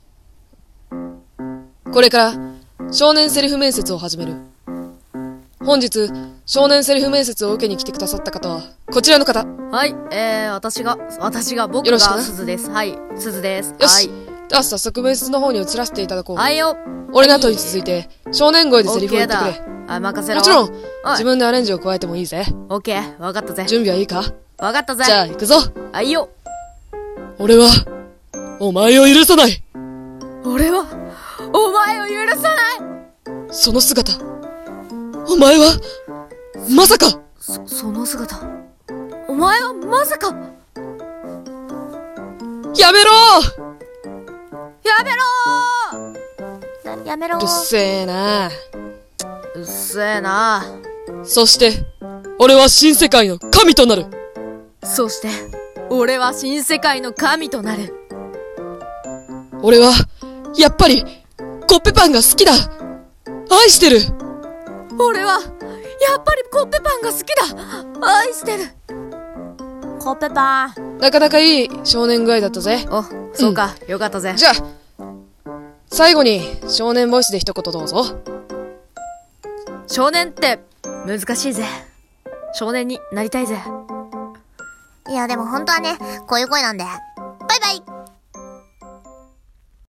少年セリフ面接（厨二）